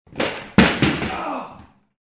Звуки стула
Звук мужчины упавшего со стула (ему больно)